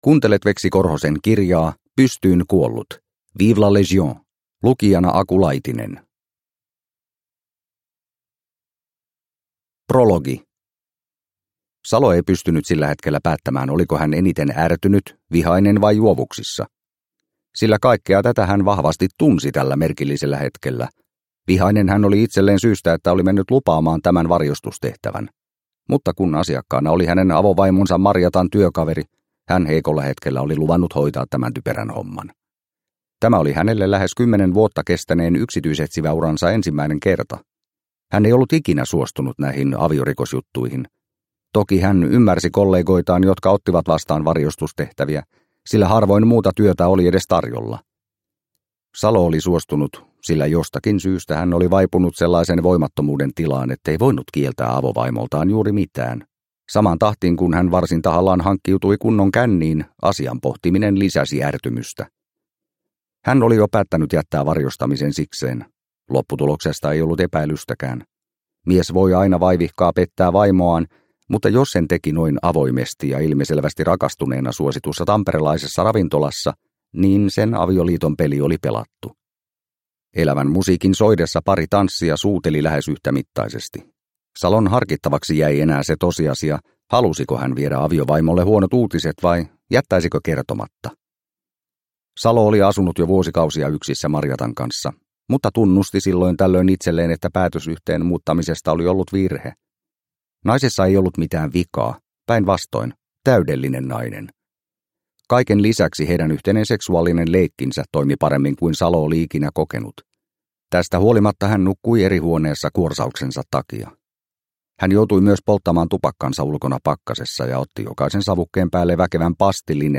Pystyyn kuollut – Ljudbok – Laddas ner